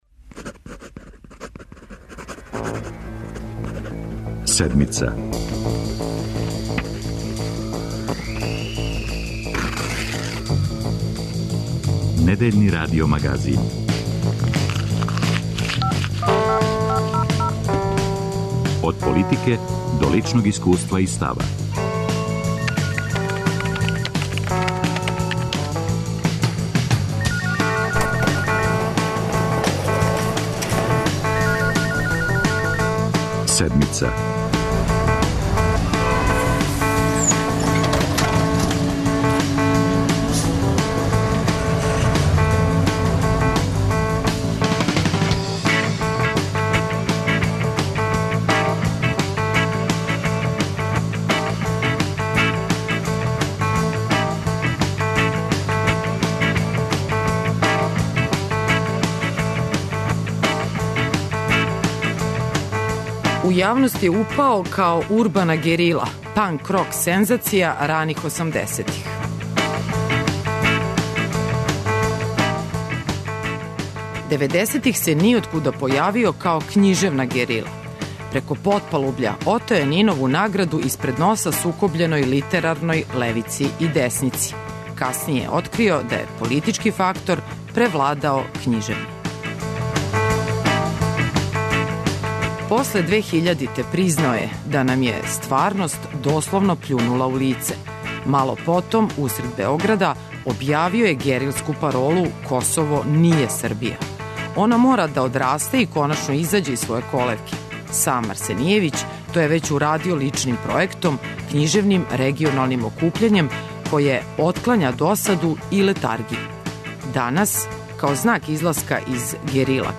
Гост Седмице је писац Владимир Арсенијевић.